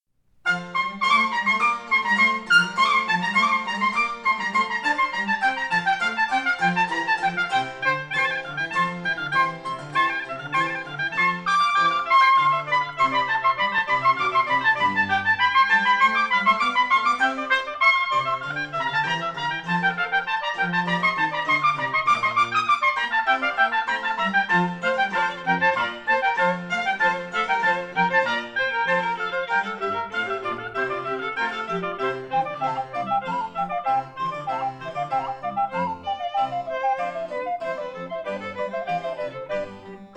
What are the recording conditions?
1960 stereo recording made by